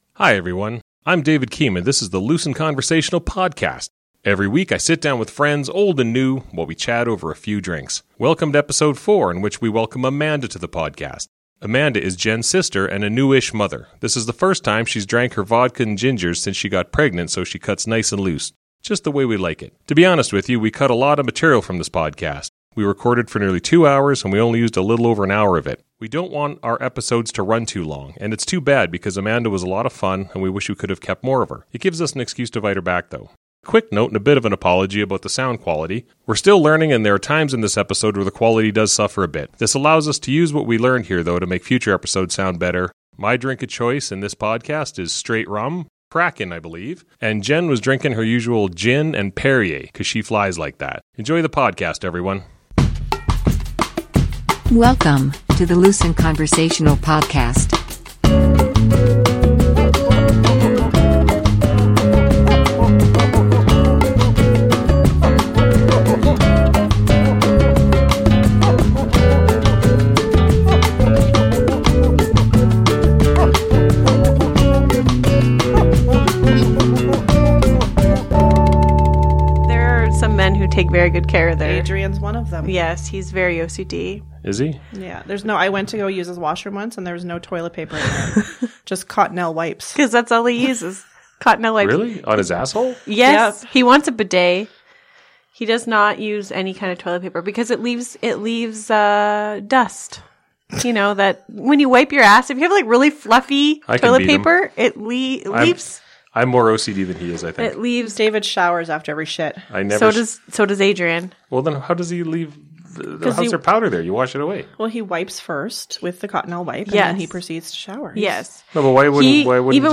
We recorded for nearly two hours and we only used a little over an hour of it.
We are still learning and there are times in this episode where the quality suffers a bit.